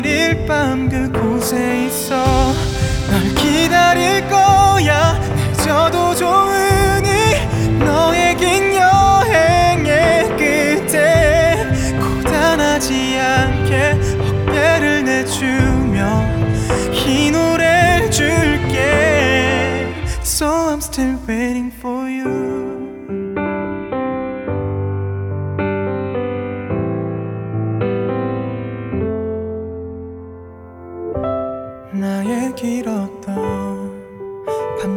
Жанр: K-pop / Поп